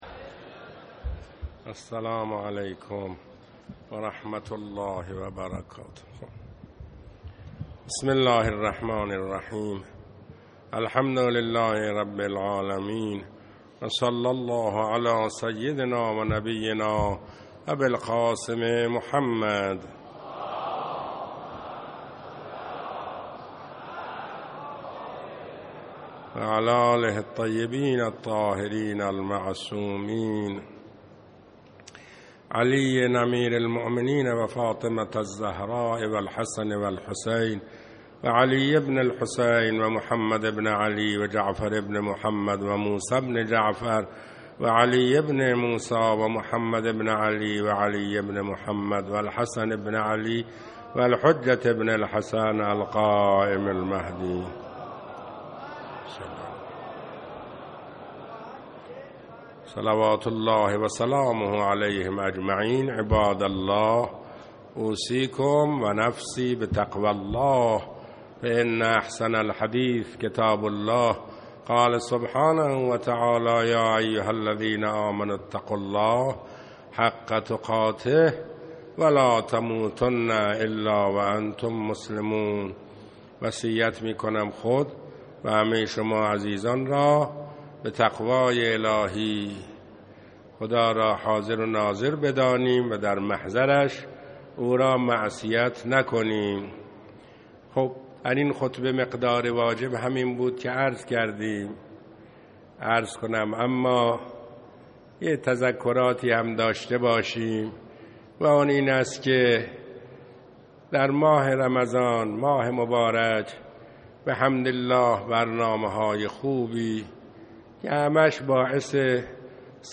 خطبه اول